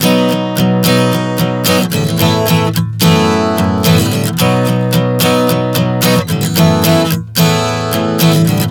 Prog 110 B-A-F#m11.wav